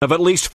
Many of the most common little words of English are usually pronounced with a weak, colourless vowel ‘schwa’, /ə/.